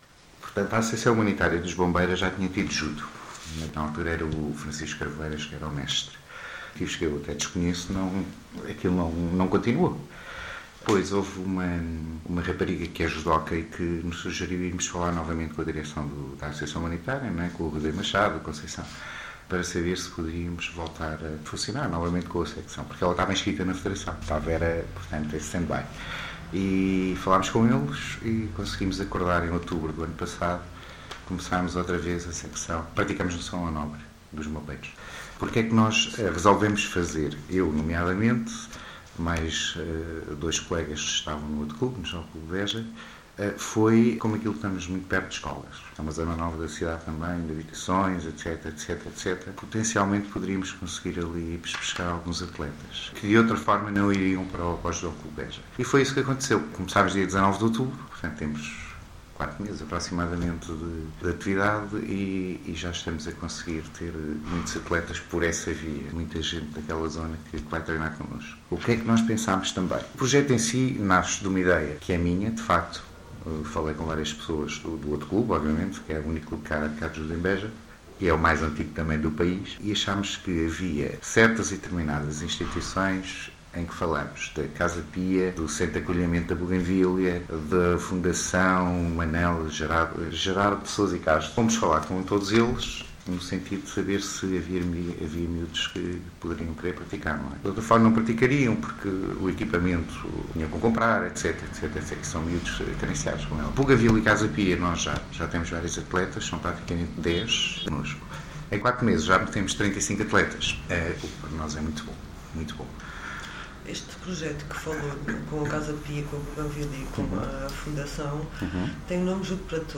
A entrevista da semana no Programa Cidade Viva